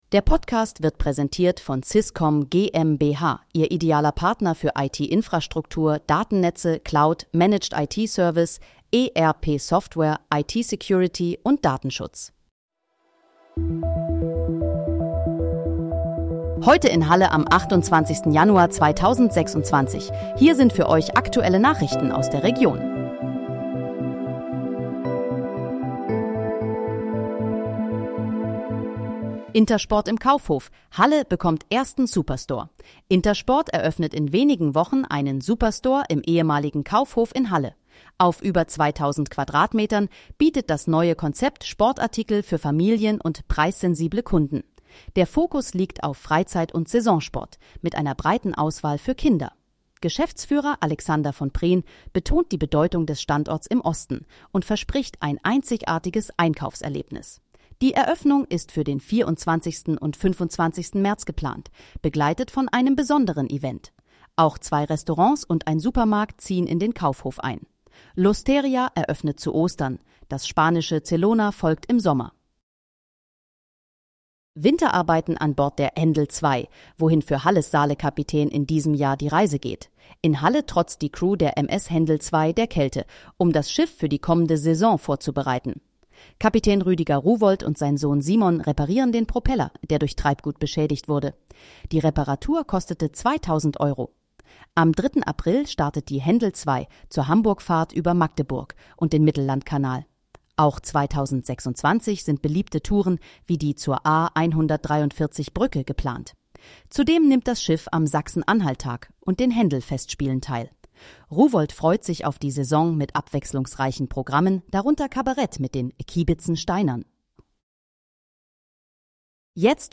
Heute in, Halle: Aktuelle Nachrichten vom 28.01.2026, erstellt mit KI-Unterstützung
Nachrichten